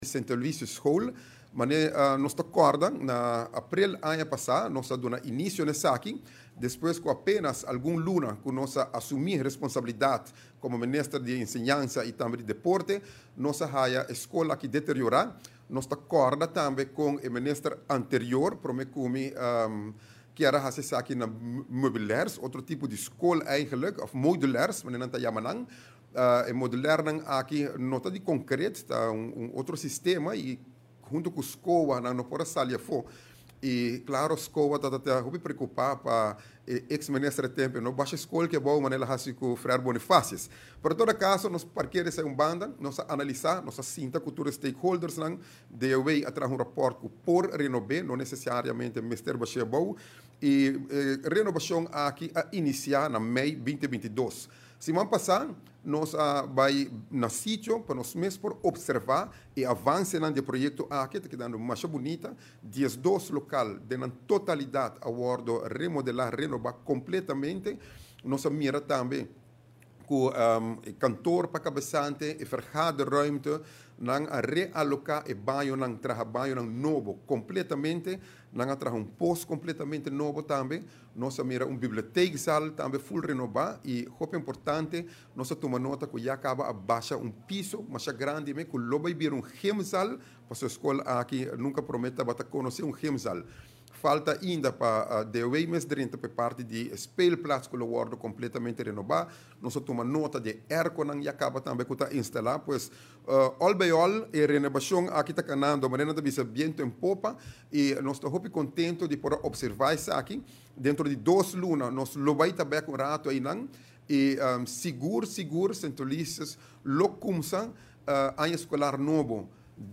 Den conferencia di prensa minister di enseñansa Endy Croes a expresa cu e ta sumamente contento cu e progreso di St. Aloysius school ta canando. Segun e mandatario e scol lo hasta haya un gymzaal cu e no tabatin.